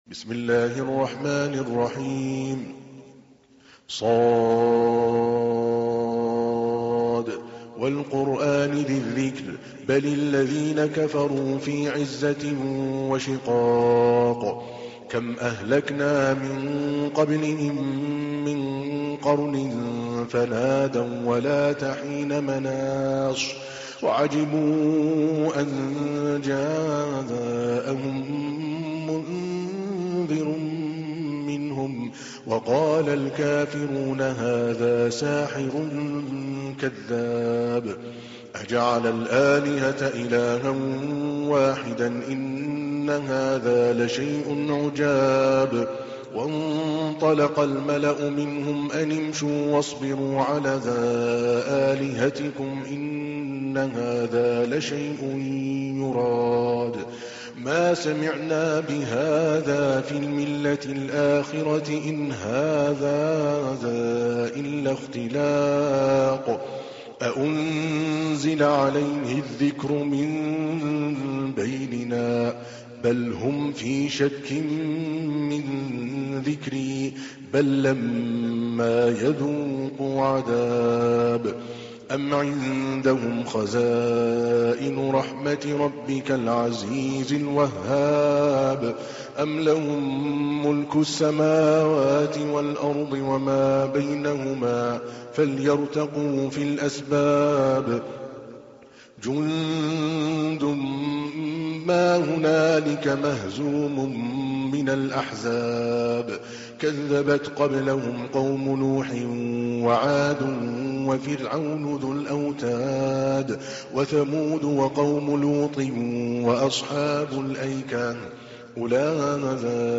تحميل : 38. سورة ص / القارئ عادل الكلباني / القرآن الكريم / موقع يا حسين